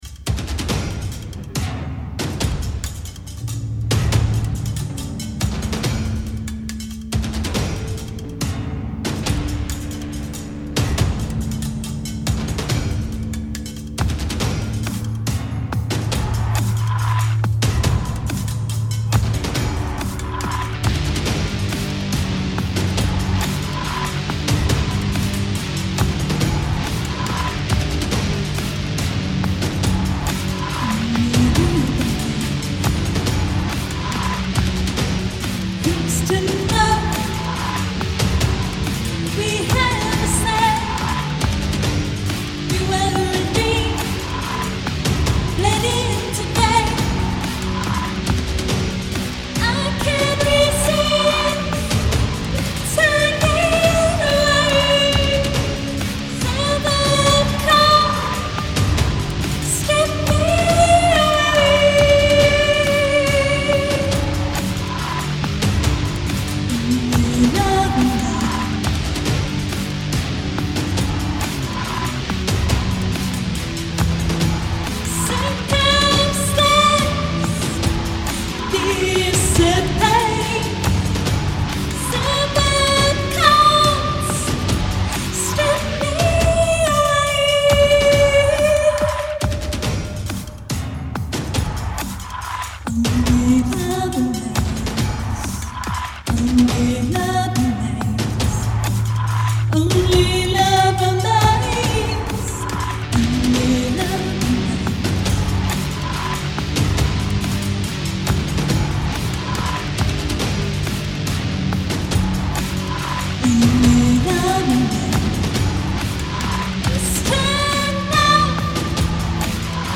Electronic music duo